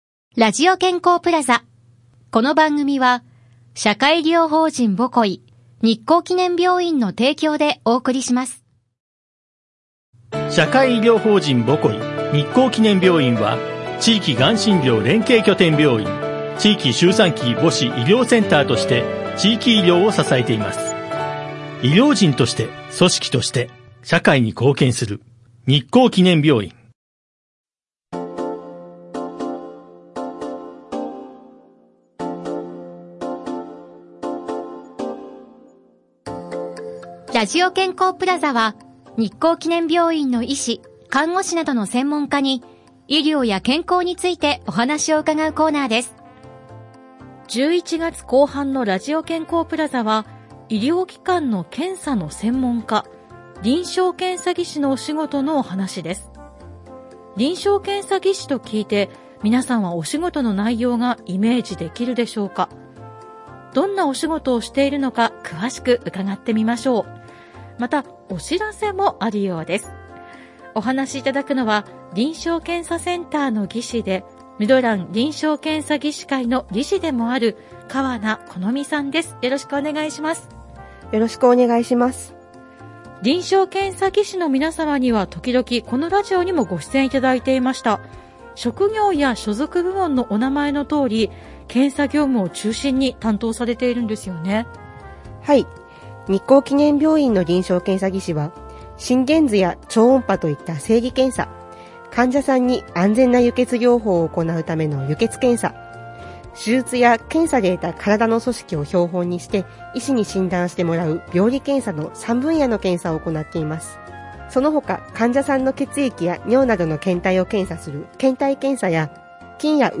室蘭市のコミュニティFM『FMびゅー』から、様々な医療専門職が登場して、医療・健康・福祉の事や病院の最新情報など幅広い情報をお届けしています。